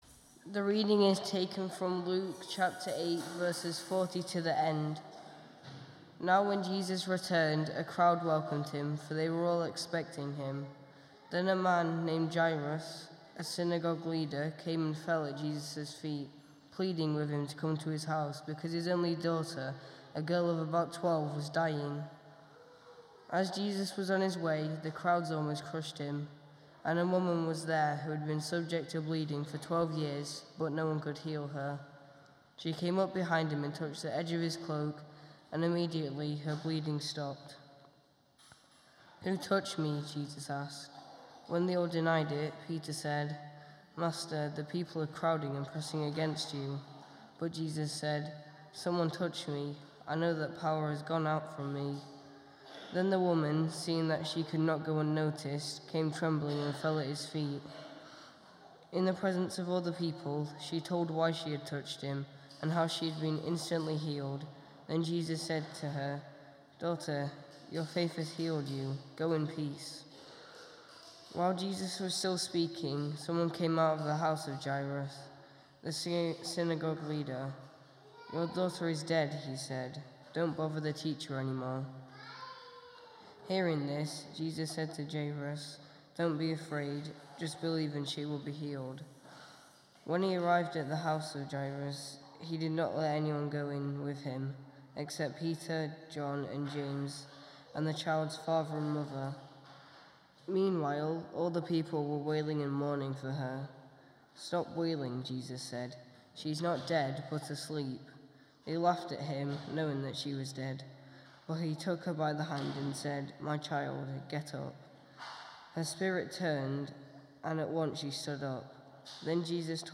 Passage: Luke 8:40-56 Service Type: Sunday Morning